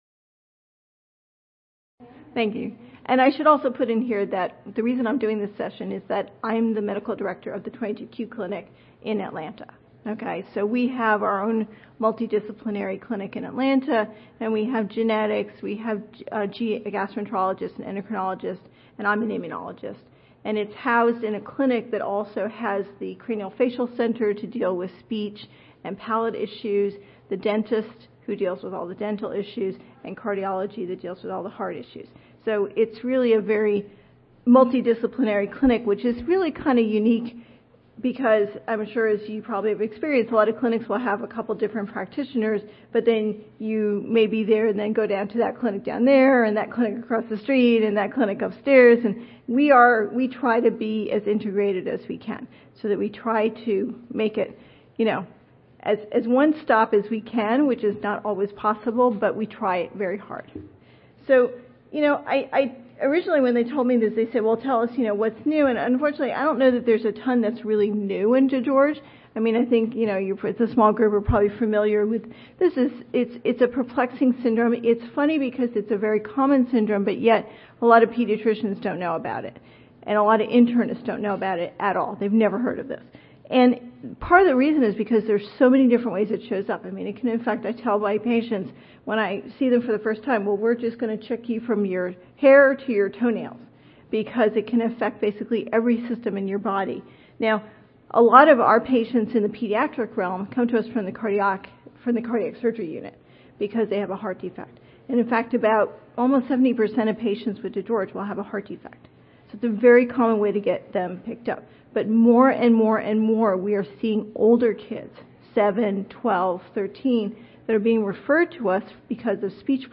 Emory Children's Center Audio File Recorded Presentation See more of